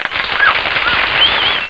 Clapping.aif
clapping.aif